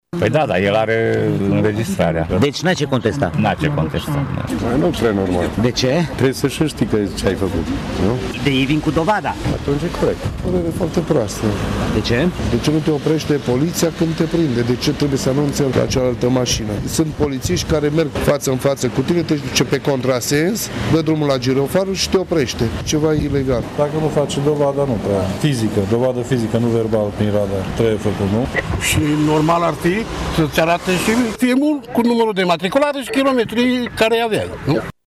Măsura e corectă, cred unii șoferi târgumureșeni, alții consideră că unii polițiști vor profita de această modificare: